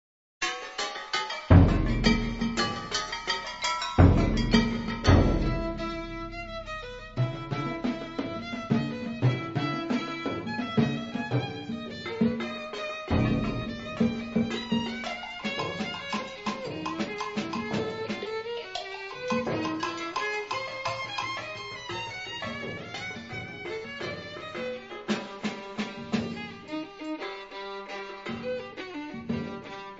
• registrazione sonora di musica